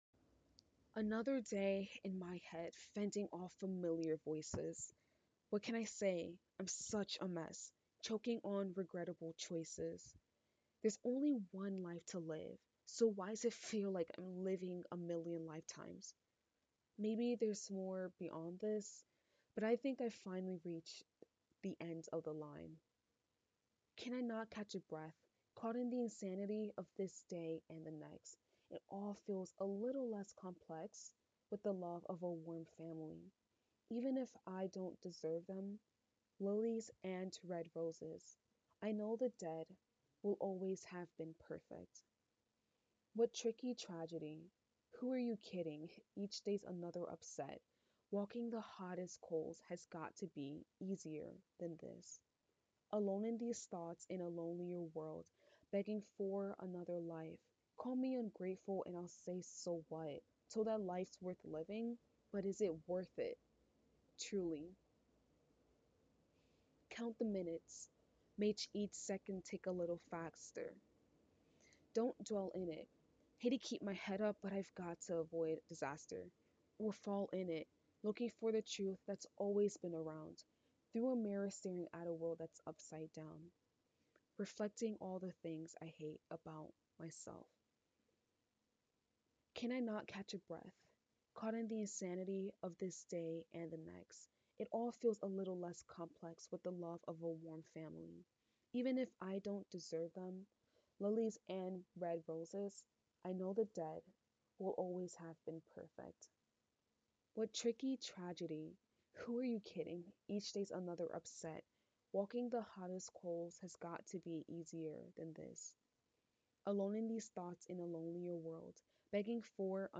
spoken word (demo)